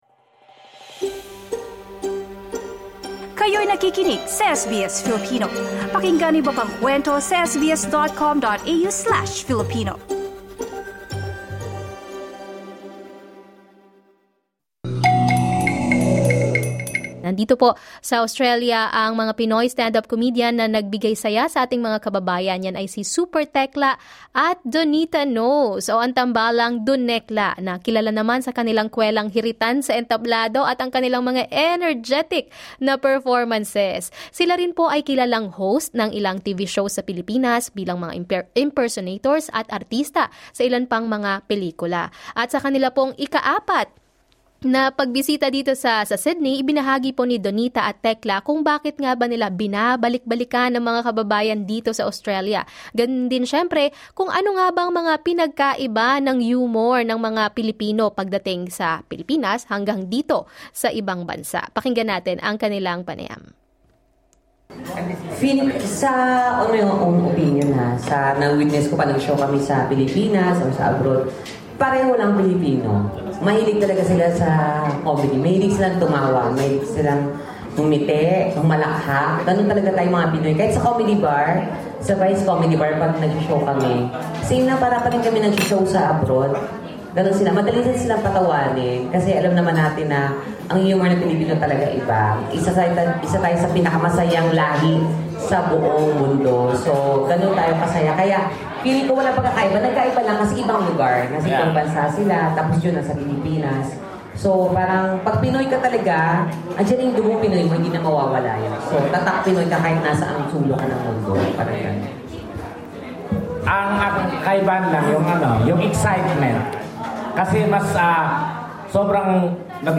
Following the celebration of Valentine's Day, comedic duo Donita Nose and Super Tekla returned to Australia, bringing laughter to their fellow Filipinos. In an interview, they shared the secret behind their successful partnership and reflected on why Filipinos are among the happiest people in the world.